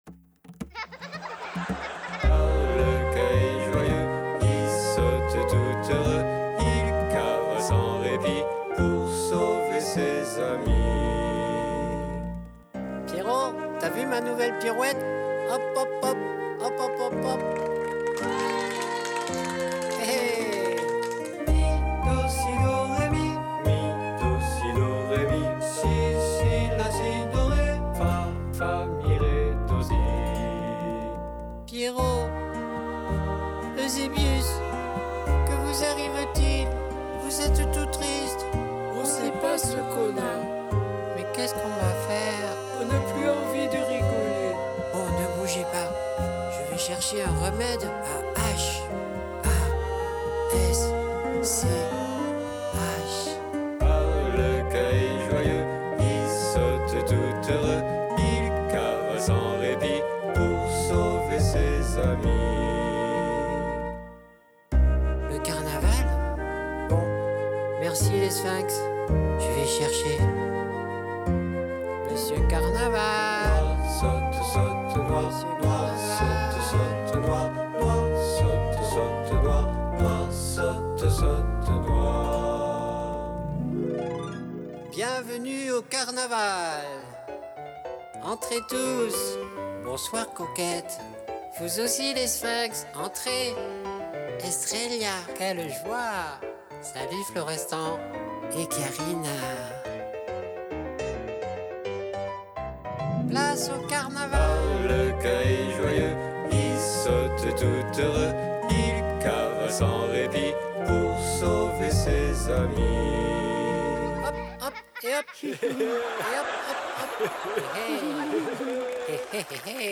Découvrez une activité manuelle sur le thème du Carnaval et de Mardi Gras pour des enfants de maternelle / primaire, un jeu de musique et un conte musical.